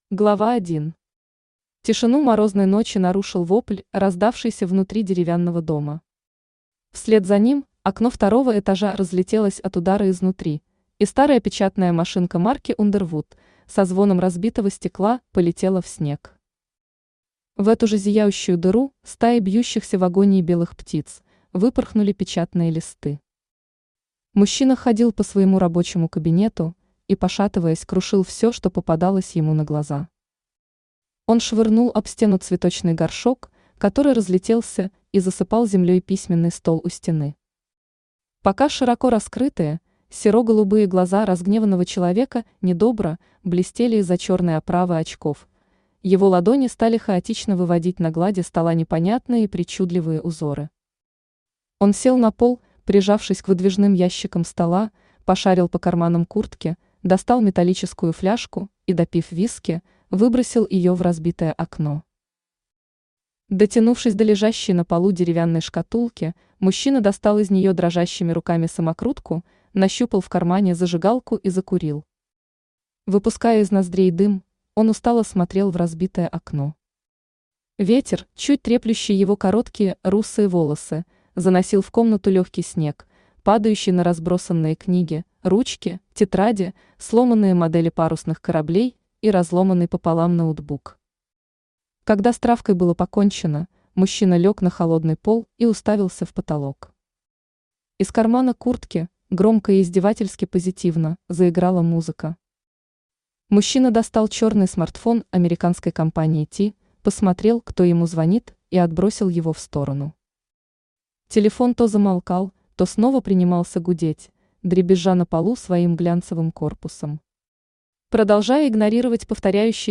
Aудиокнига Чернильная девушка Автор Анри Старфол Читает аудиокнигу Авточтец ЛитРес.